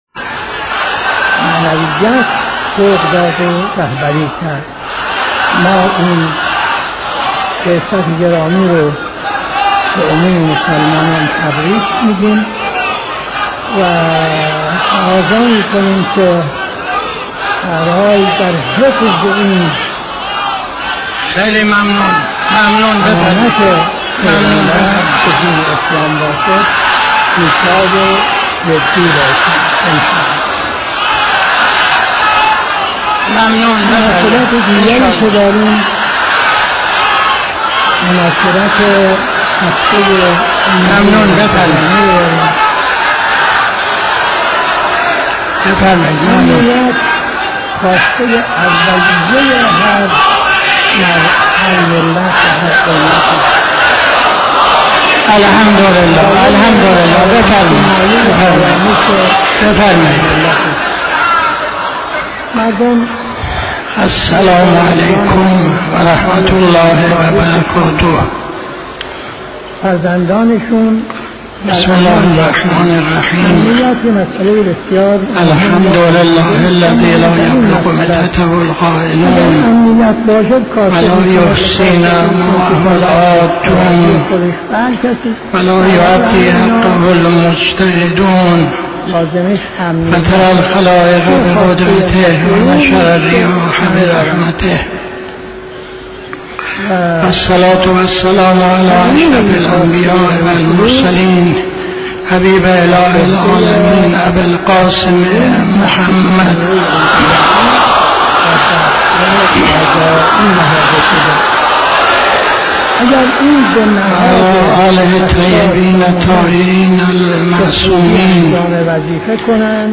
خطبه اول نماز جمعه 04-11-81